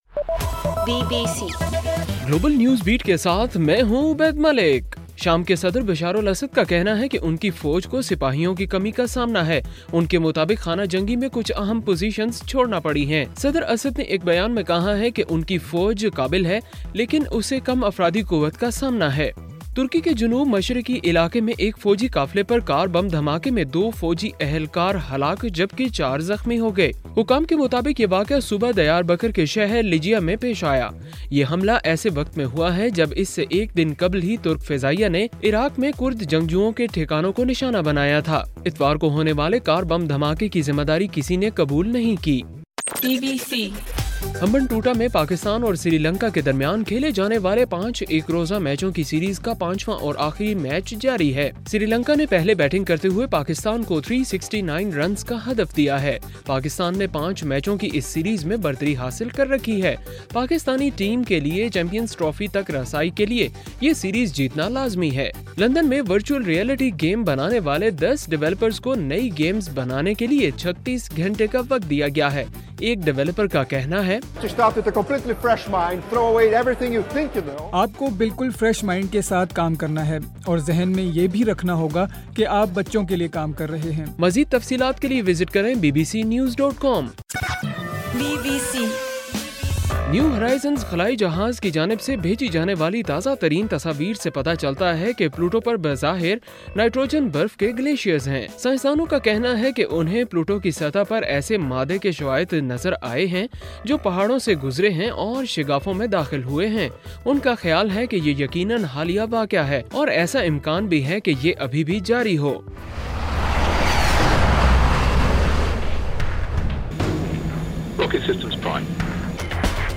جولائی 26: رات 9 بجے کا گلوبل نیوز بیٹ بُلیٹن